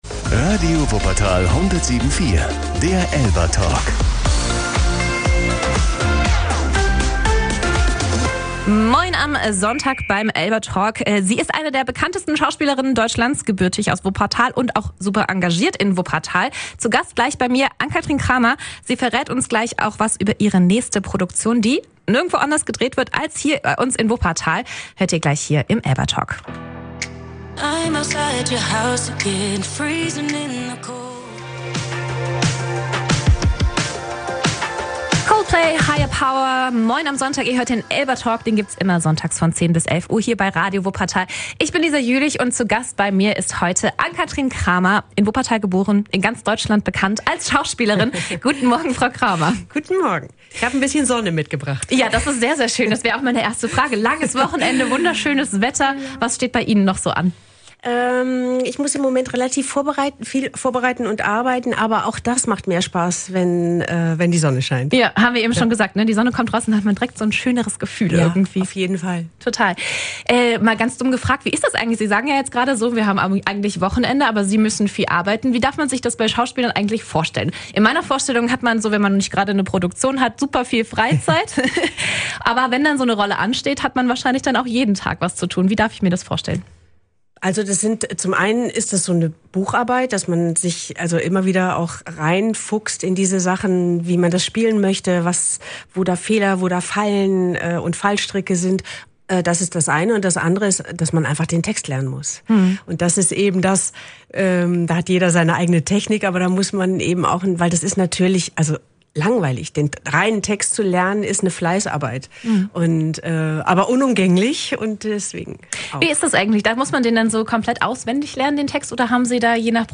Außerdem hat sie auch über ihr Engagement beim Verein Chance8 gesprochen. Und so viel sei gesagt: Man hört wie ihr dabei das Herz aufgeht.